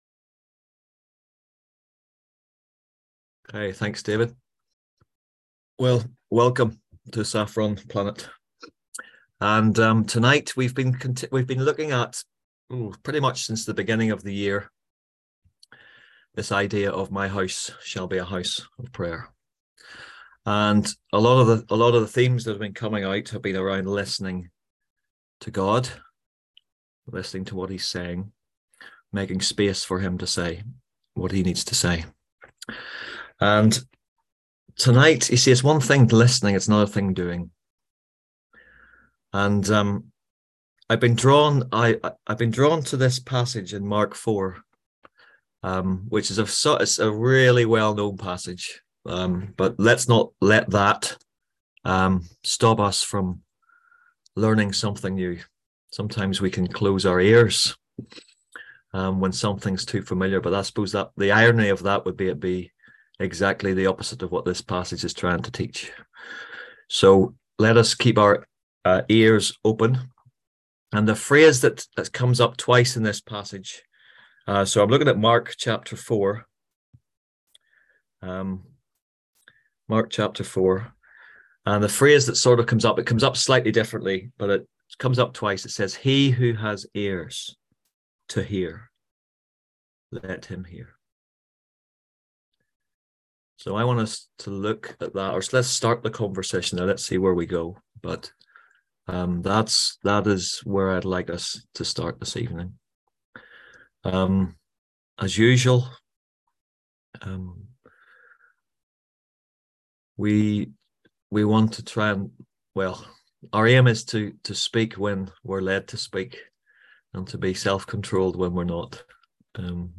On March 6th at 7pm – 8:30pm on ZOOM